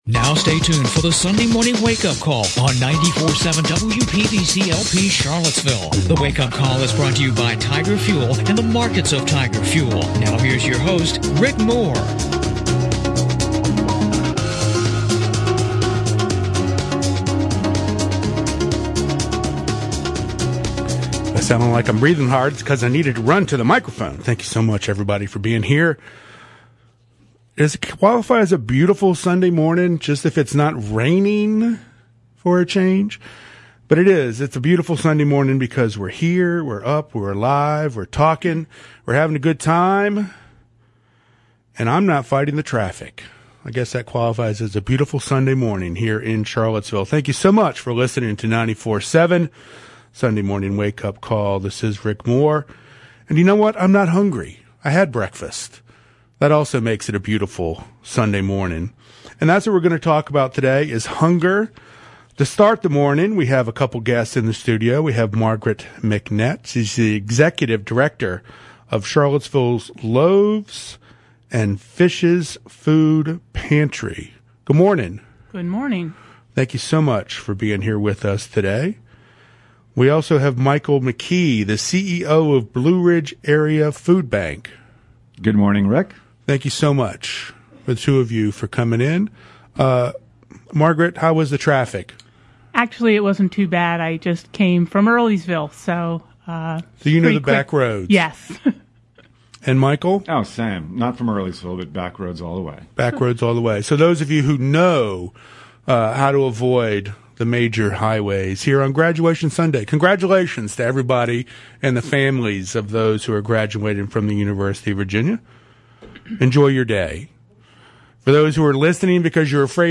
The meeting was held on Zoom. Following the presentation, questions were taken from the audience.